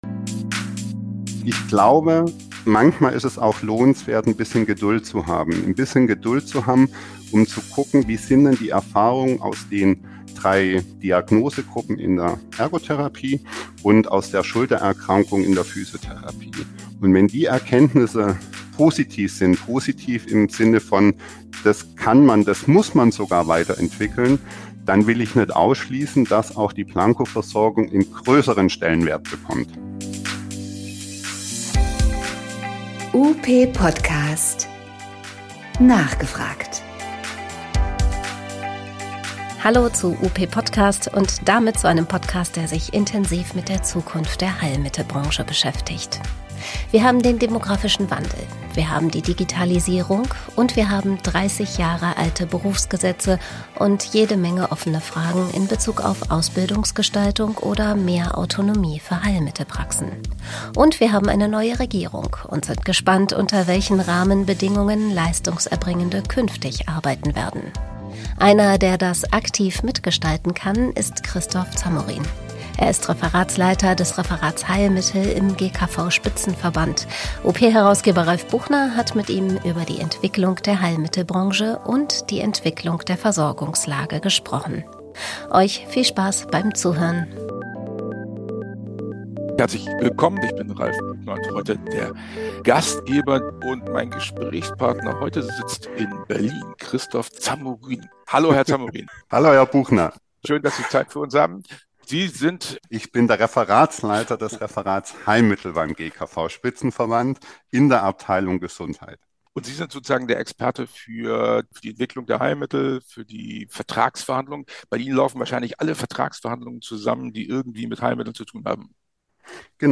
Einblick in die Arbeit des GKV-Spitzenverbandes: Interview